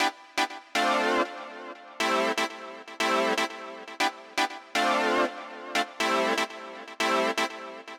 23 ChordSynth PT1.wav